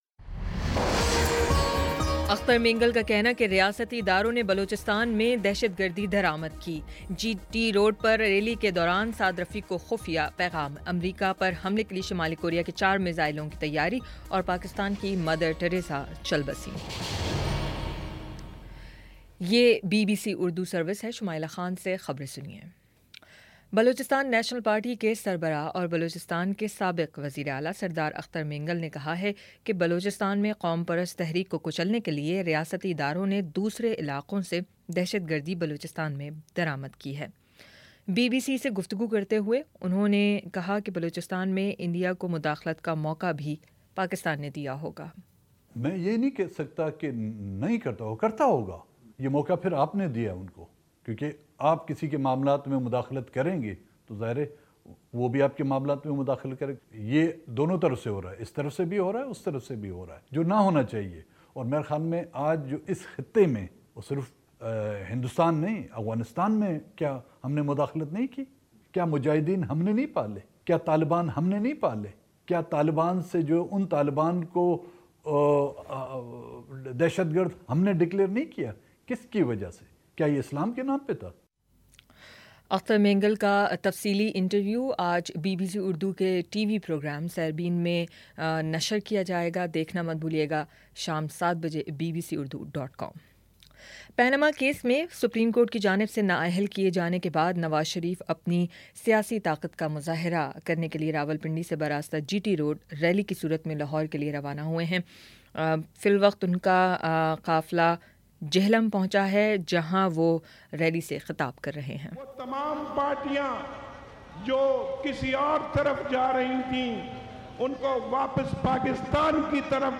اگست 10 : شام چھ بجے کا نیوز بُلیٹن